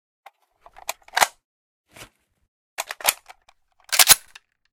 svt40_reload_empty.ogg